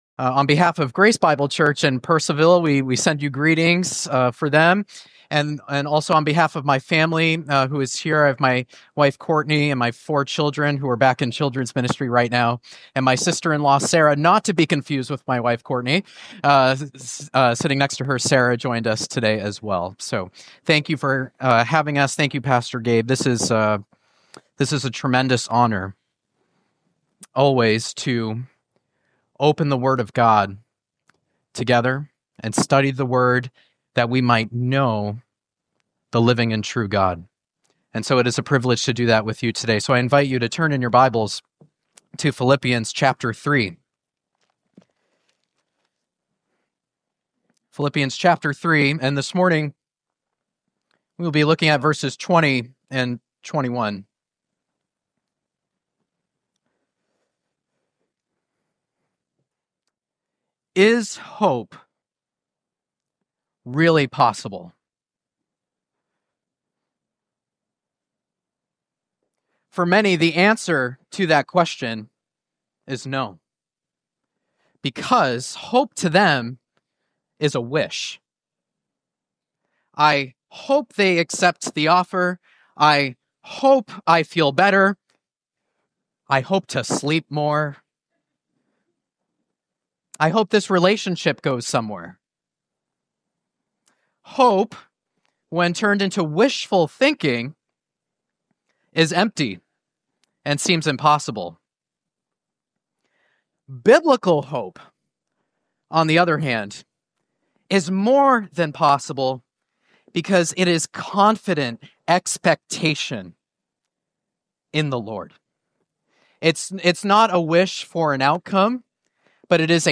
Recent Sermons - Hope Bible Church
Category: Sunday Service